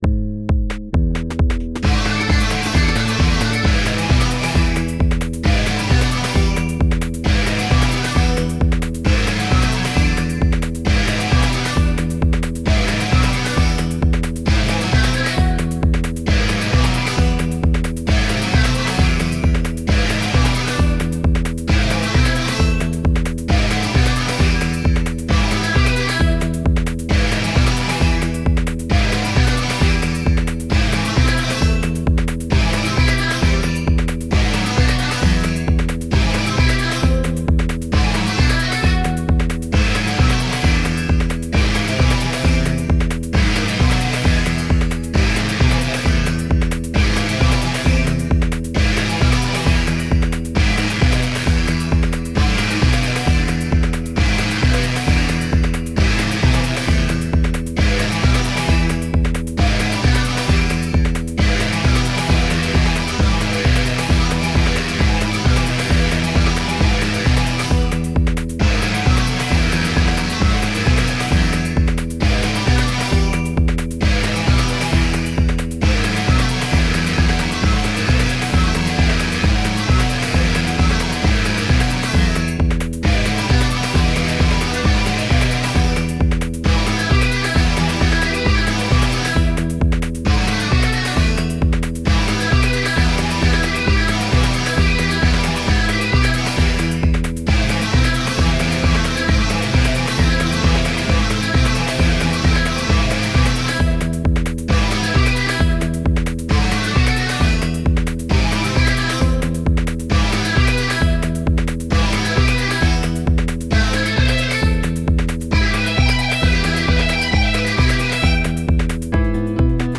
Here's one from the other day that uses SmplTrek for bass and drums and to drive Rozeta Arp and kinda suffers from how SmplTrek's limited gate length makes it not the best sequencer for running arps!
The piano in the middle is a loop sample that I created in GarageBand using its "auto play" feature 🤦🏻‍♀
The synth that the arp is driving is KQ Dixie♥♥♥♥♥♥